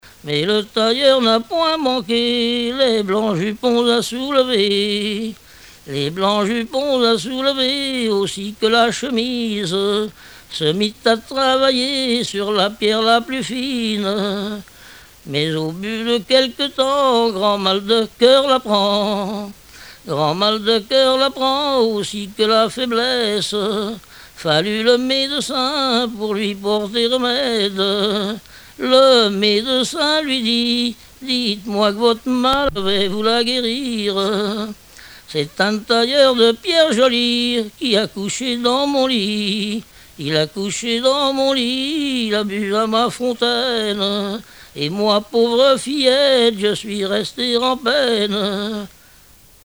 Genre strophique
chansons populaires et traditionnelles